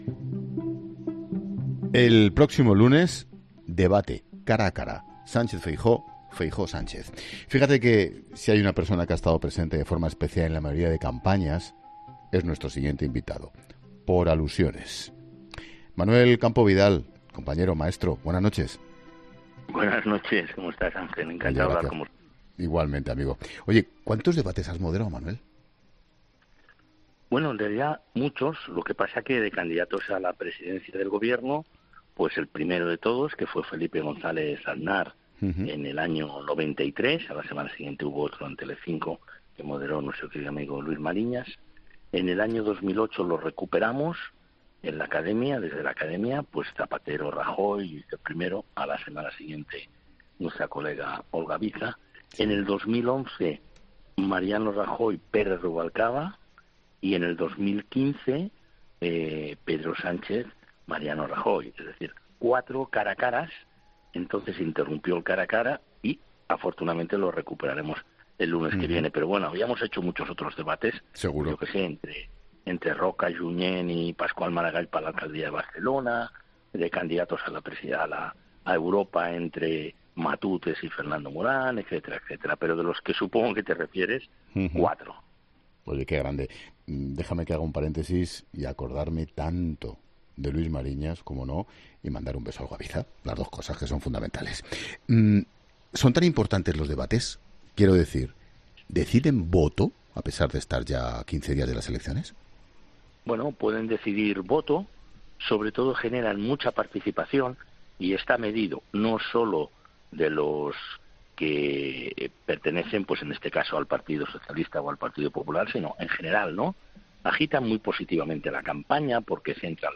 Escucha el análisis del periodista Manuel Campo Vidal sobre el papel del debate en el voto para el 23J
Para analizar cómo repercuten en el voto, Ángel Expósito ha consultado al periodista Manuel Campo Vidal.